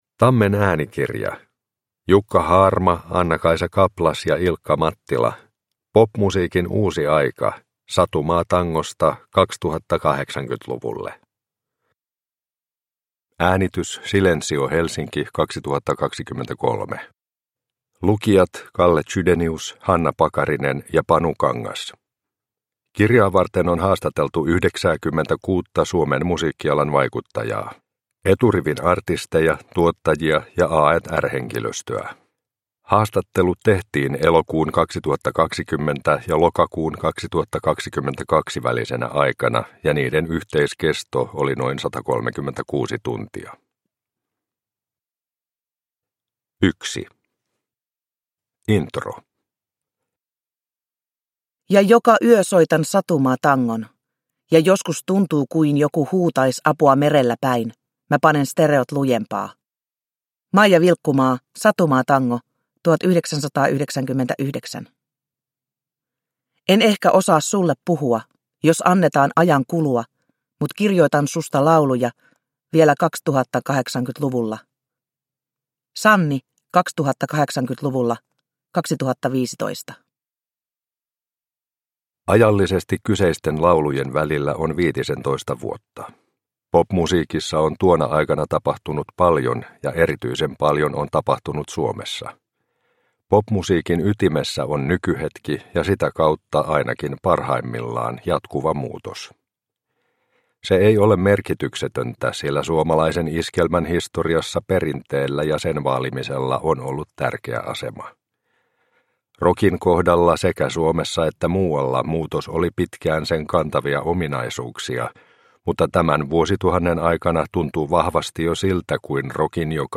Popmusiikin uusi aika – Ljudbok – Laddas ner